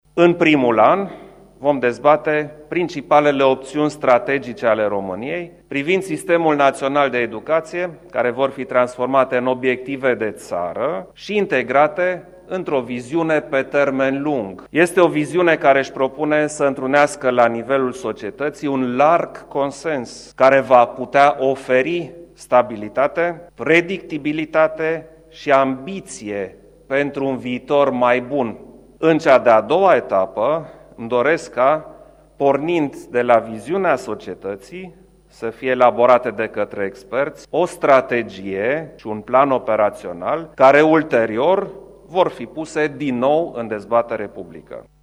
Preşedintele Klaus Iohannis: